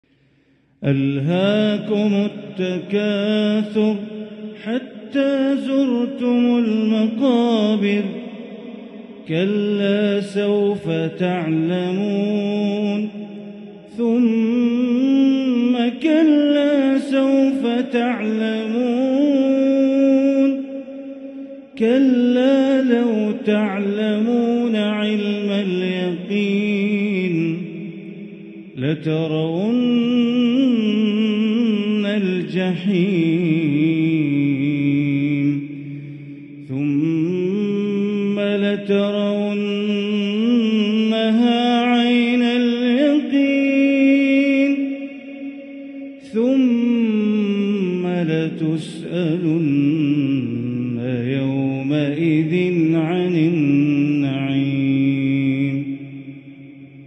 سورة التكاثر > مصحف الحرم المكي > المصحف - تلاوات بندر بليلة